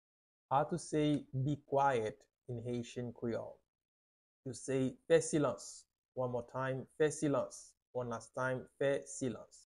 How to say “Be Quiet” in Haitian Creole - “Fè silans” Pronunciation by a native Haitian Teacher
“Fè silans” Pronunciation in Haitian Creole by a native Haitian can be heard in the audio here or in the video below:
How-to-say-Be-Quiet-in-Haitian-Creole-Fe-silans-Pronunciation-by-a-native-Haitian-Teacher.mp3